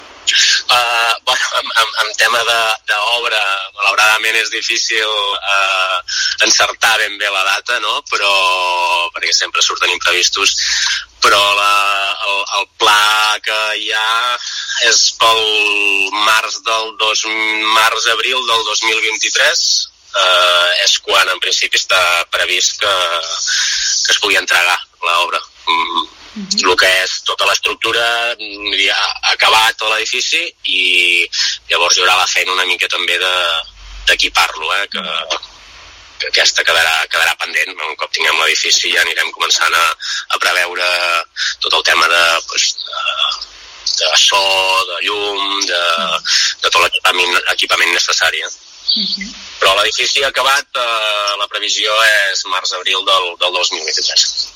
L’alcalde del municipi espera que les obres de l’edifici s’acabin entre el març o l’abril de 2023.